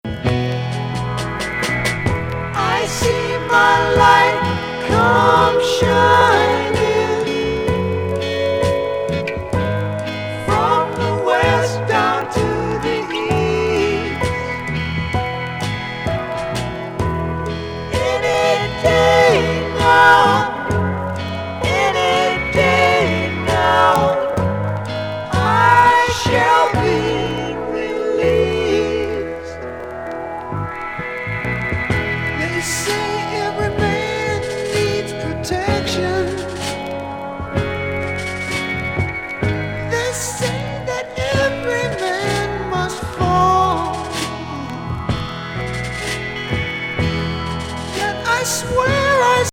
ズッポリ南部ロック・クラシック!!